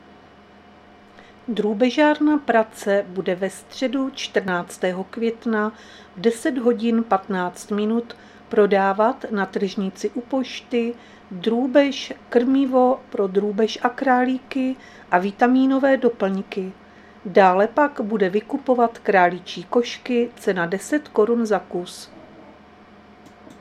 Záznam hlášení místního rozhlasu 13.5.2025
Zařazení: Rozhlas